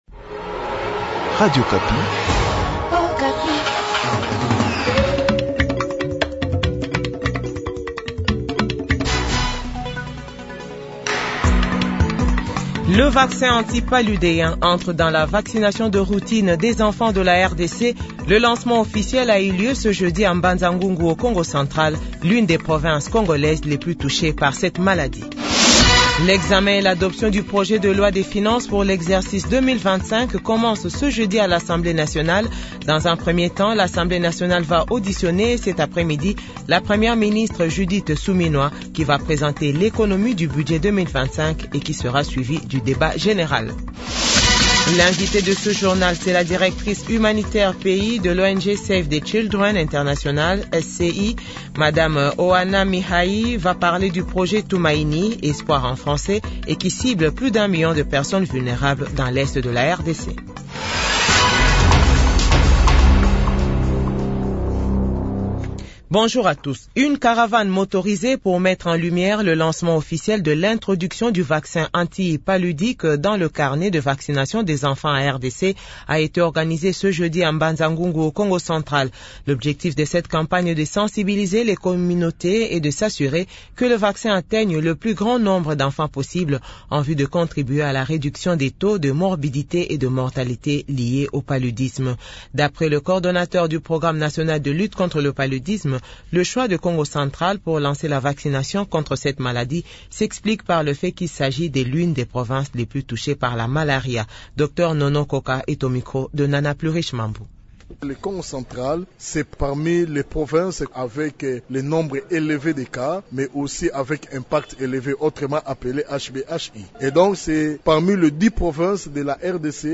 JOURNAL FRANÇAIS DE 12H00